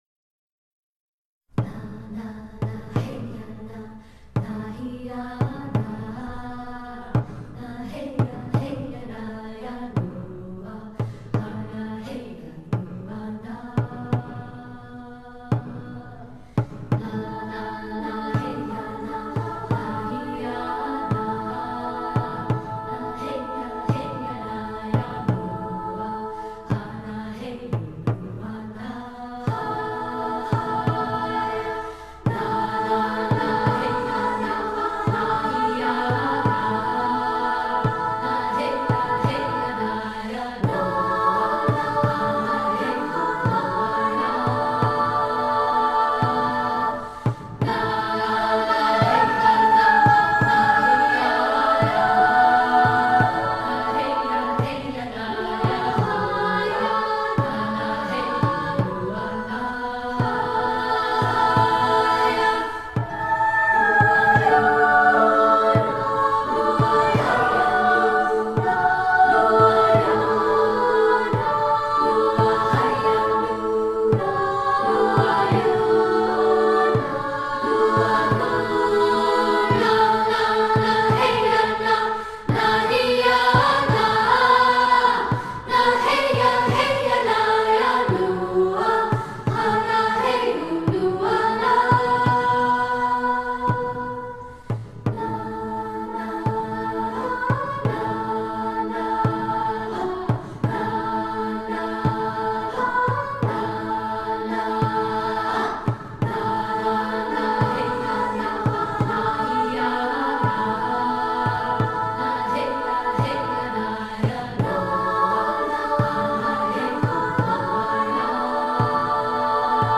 in A